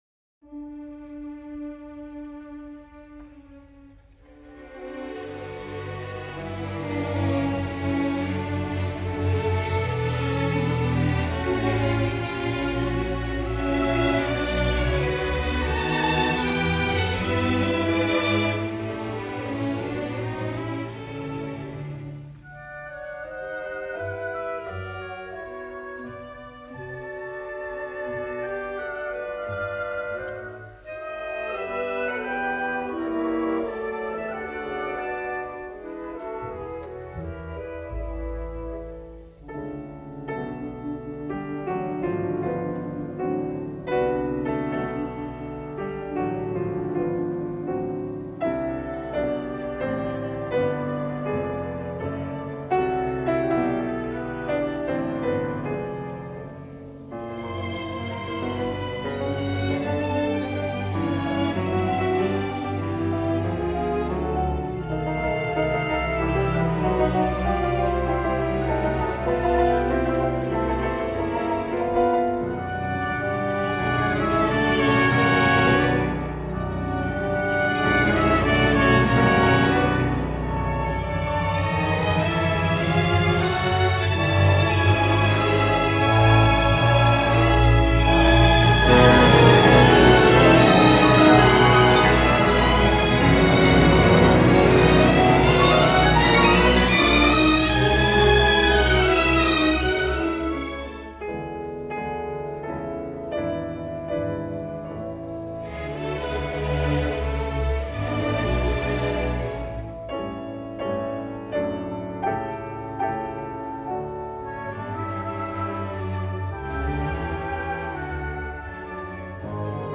Gran Concierto en Re Mayor para piano y orquesta